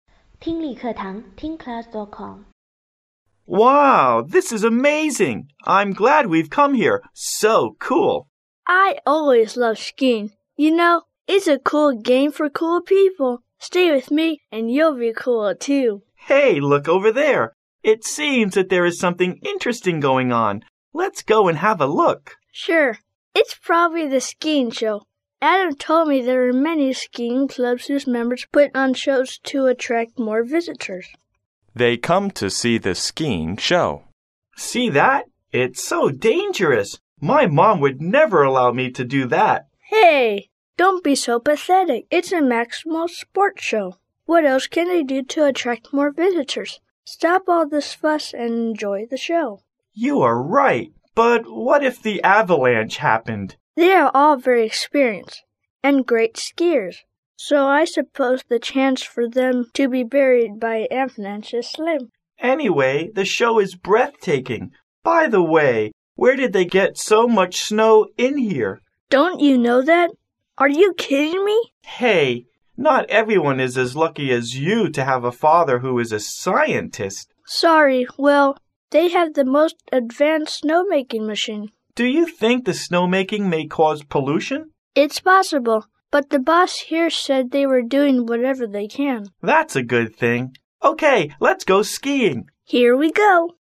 谈论滑雪表演英语对话-锐意英语口语资料库19-08